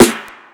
The Town Snare.wav